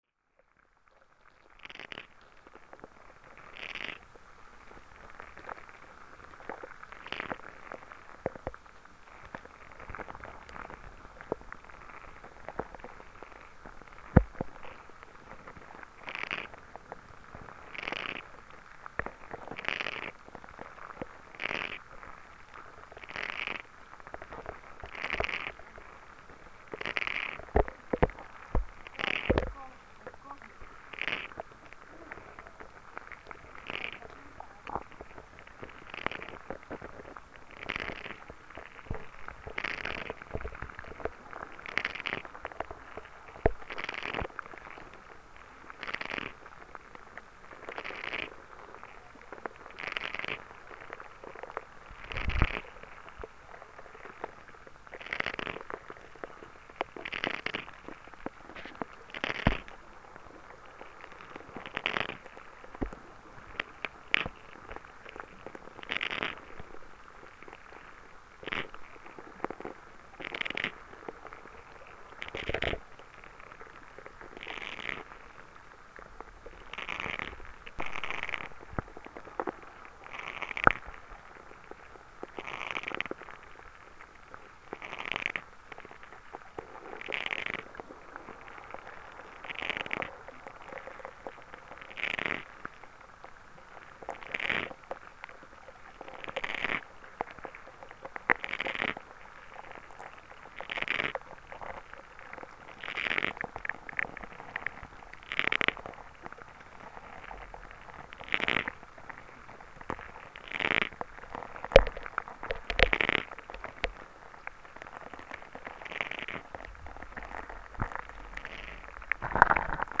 Gravación feita nunha charca na zona de Cabo Ortegal
Charca_Hidro.mp3